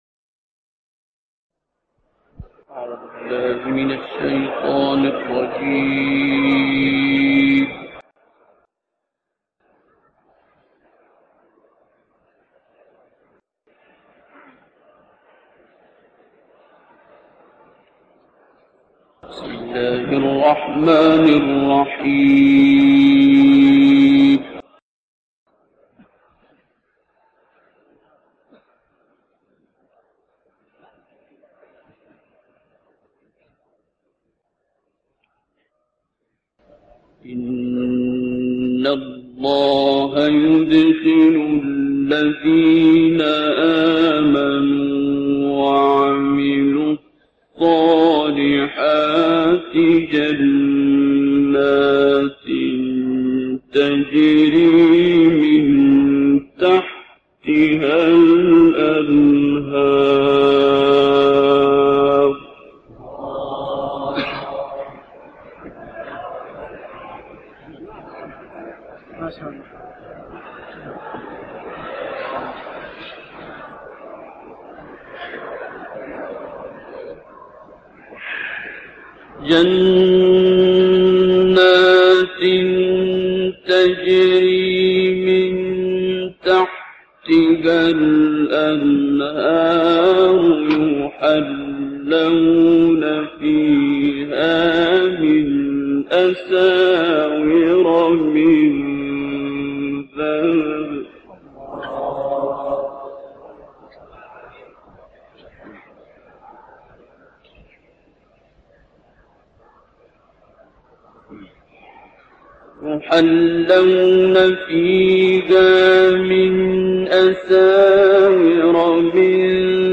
تلاوت سوره حج با صوت «عبدالباسط»
گروه شبکه اجتماعی: تلاوت آیاتی از سوره مبارکه حج را به مناسبت دهه اول ماه ذیحجه با صدای عبدالباسط محمد عبدالصمد می‌شنوید.